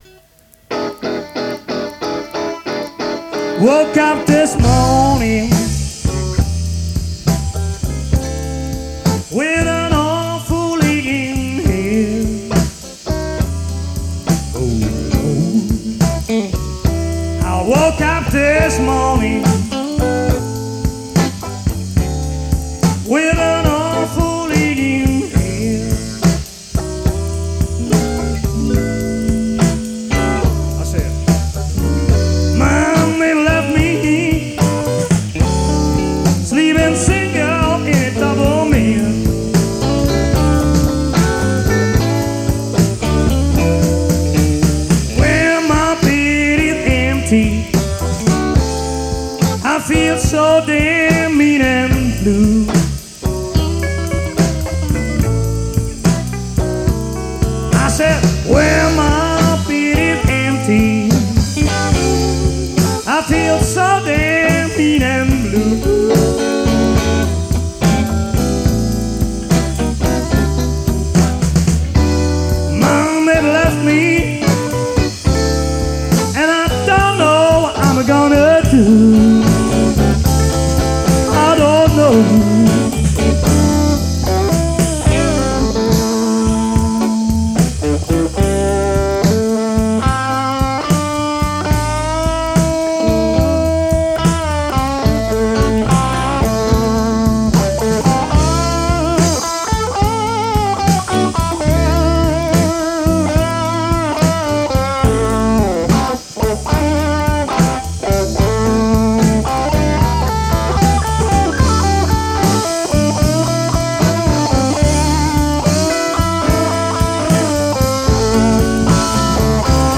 Rock 'n Roll Blues Boogie Band